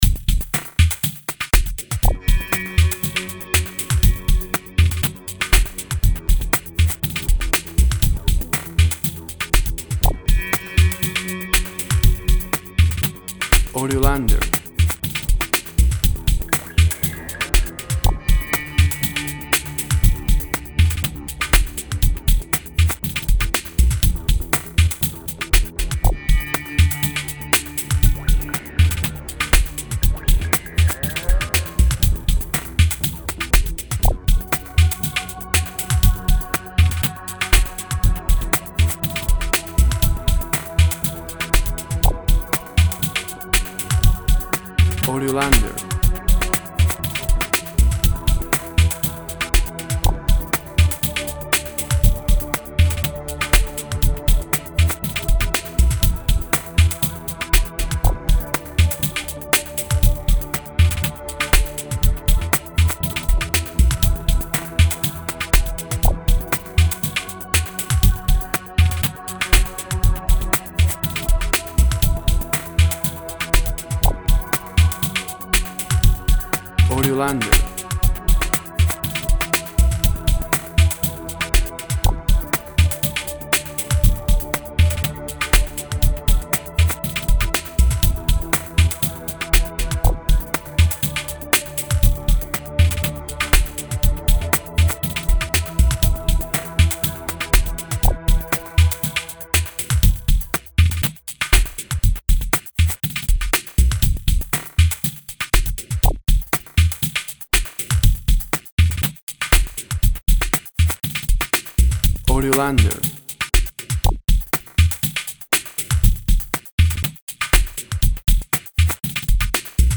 Tempo (BPM) 80